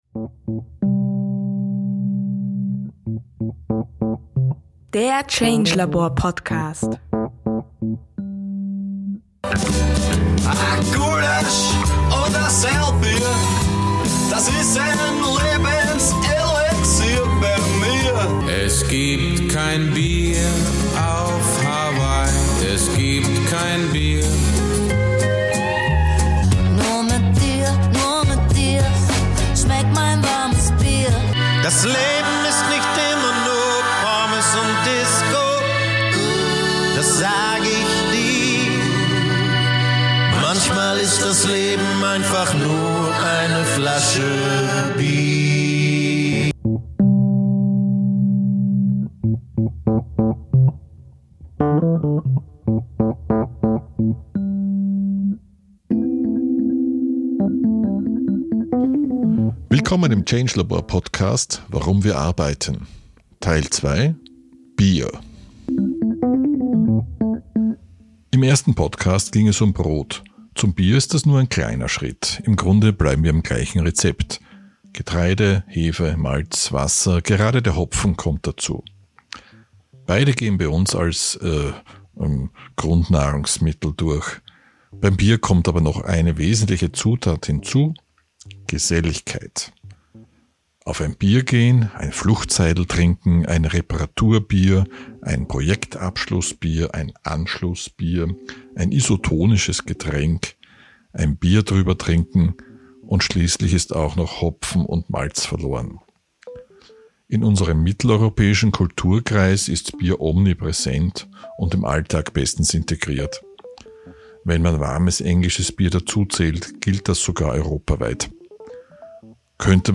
Bier ist eines der ältesten Kulturgetränke. In einem Interview mit einem der profiliertesten Getränke-Manager des Landes